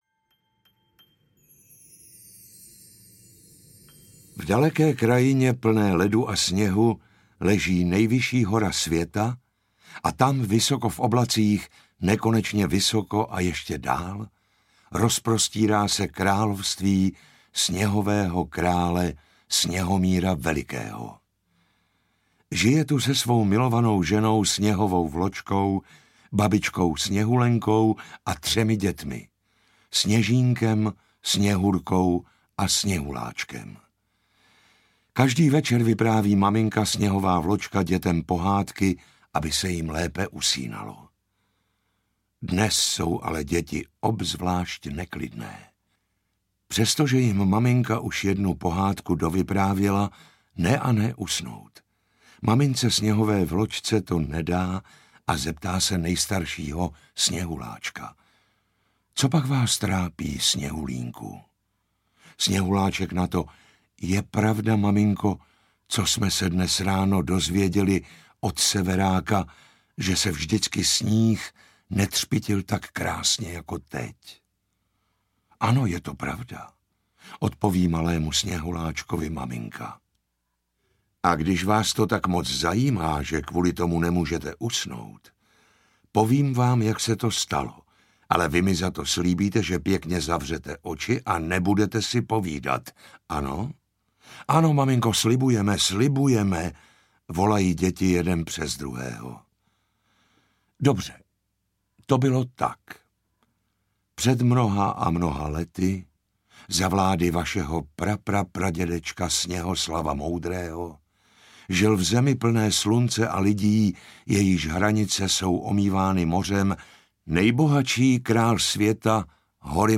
Prsten sněhového krále audiokniha
Ukázka z knihy